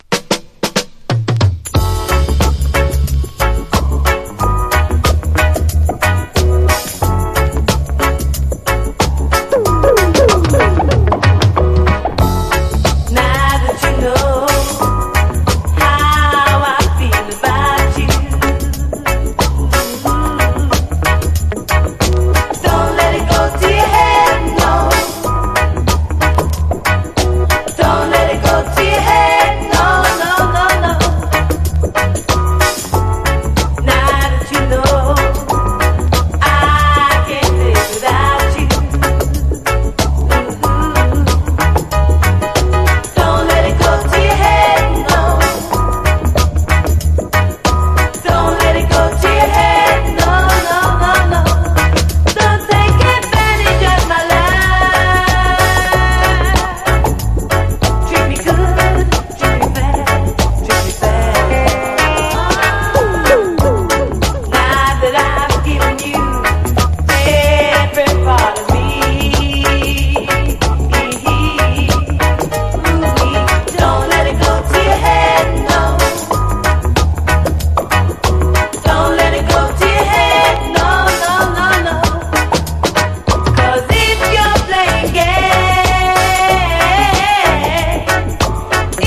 形式 : 7inch
UKガールズ・コーラス・グループによるラヴァーズ超人気ナンバー！